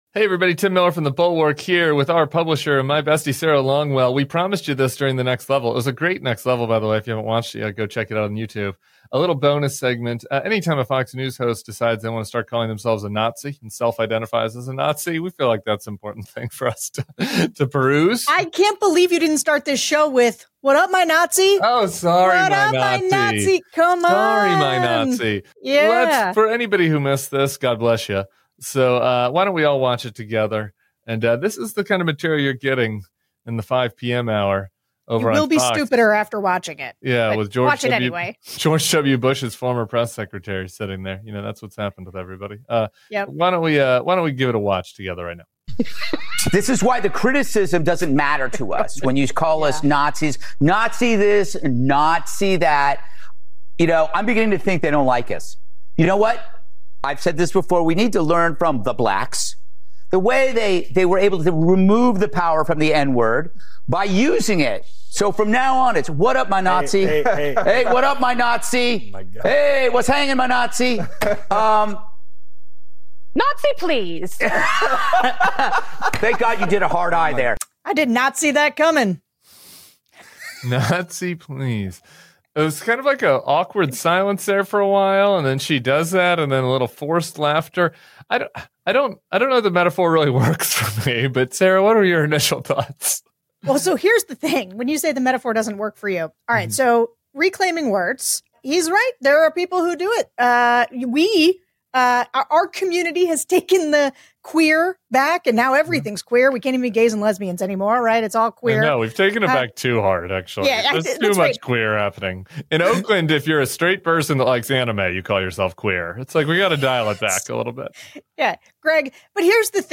Tim Miller and Sarah Longwell discuss Greg Gutfeld’s bizarre on-air attempt to joke about fascism as the trend of far-right figures hiding behind bad comedy grows, and why it’s not just a joke.